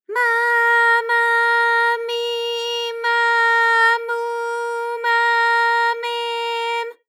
ALYS-DB-001-JPN - First Japanese UTAU vocal library of ALYS.
ma_ma_mi_ma_mu_ma_me_m.wav